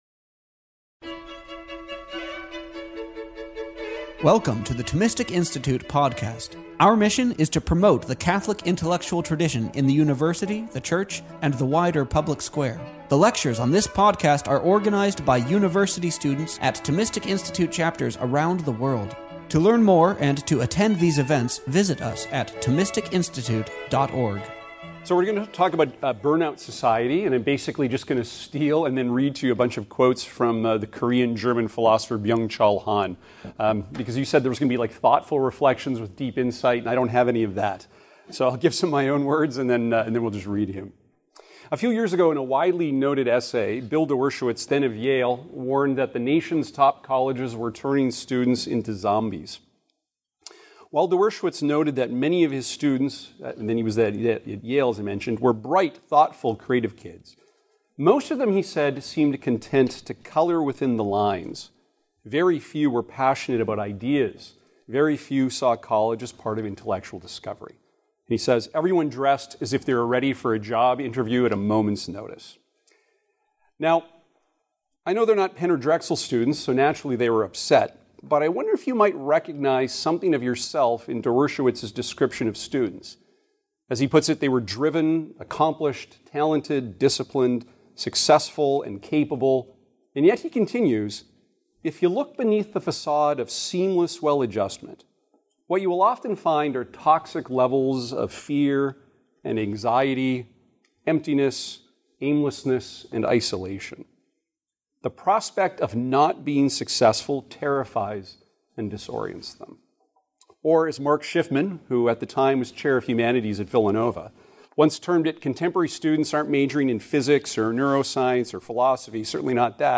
This lecture was given on December 8th, 2025, at University of Pennsylvania.